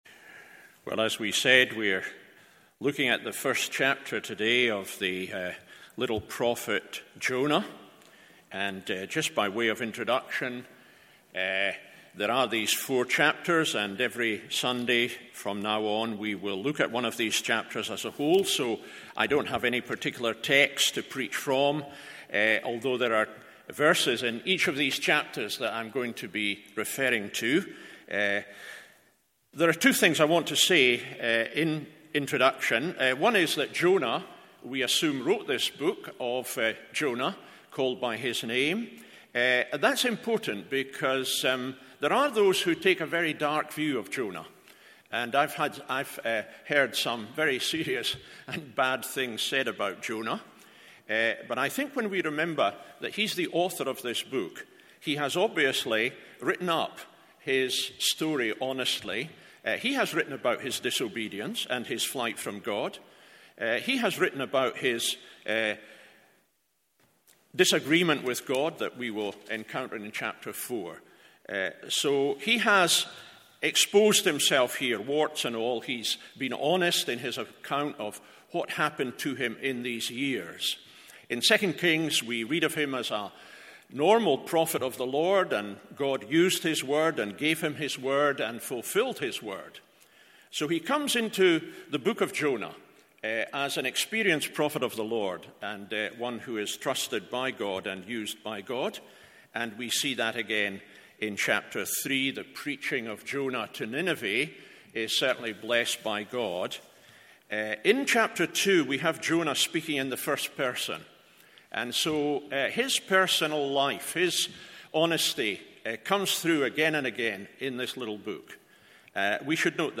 MORNING SERVICE Jonah 1…